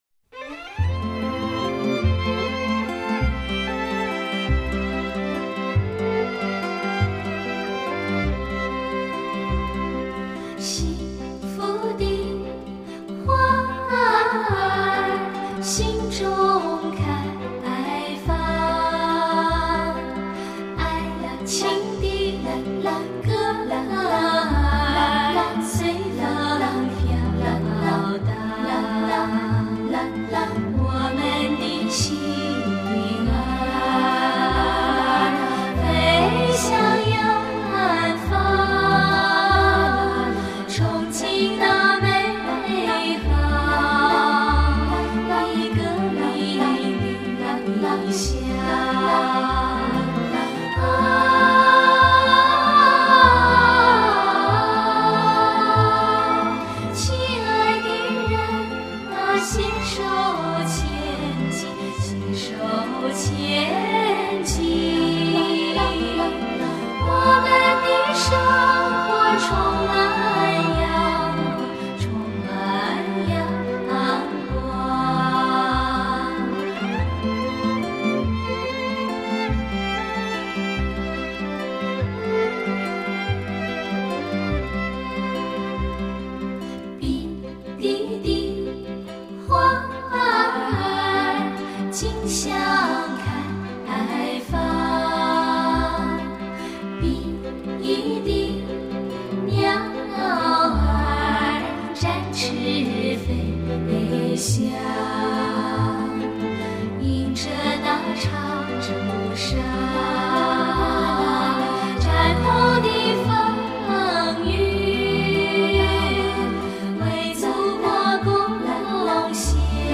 类型: 天籁人声
音色相当靓，整个录音拥有非常甜美的音色和
，细致高雅的中频和平顺、透明、安稳、柔美的整体听感，足以媲美最高级的发烧制做作。